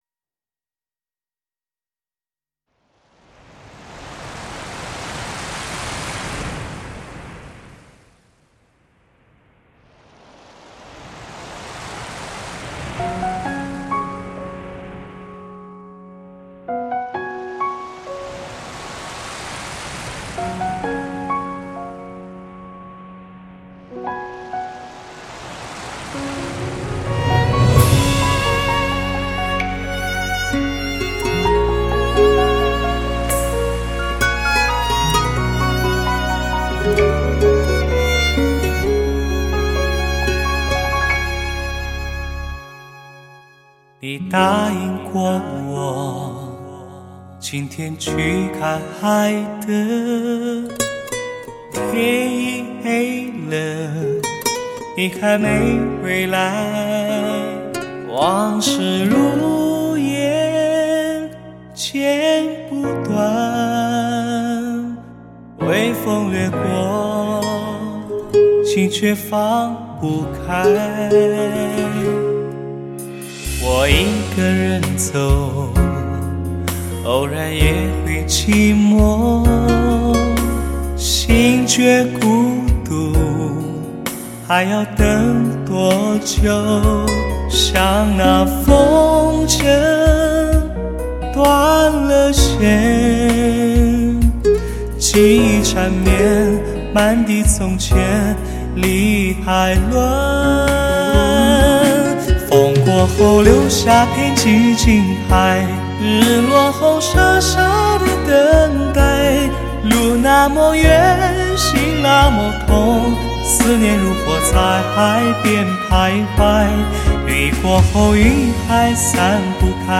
15首超级流行曲目！
至尊HI-FI享受！